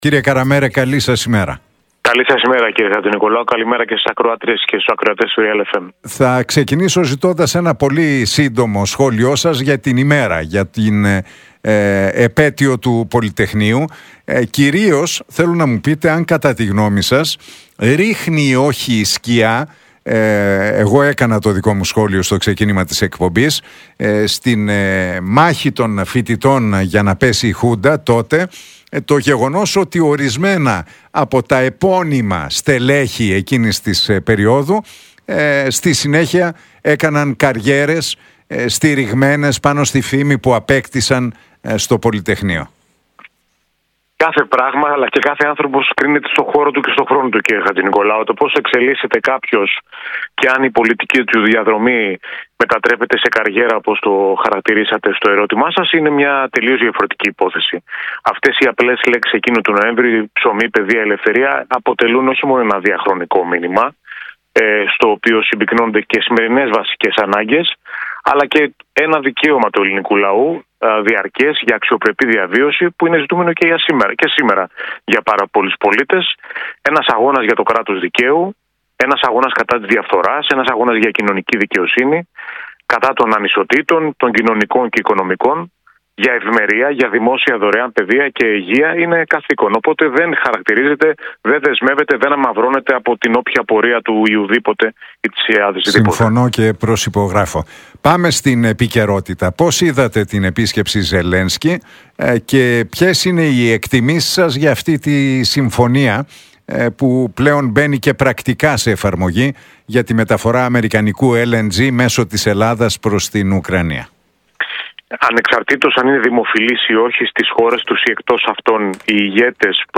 Καραμέρος στον Realfm 97,8 για Τσίπρα: Το βιβλίο του χαράζει νέα πορεία – Ο λαός θα κρίνει αν αξίζει μια πραγματική ευκαιρία — ΔΕΔΟΜΕΝΟ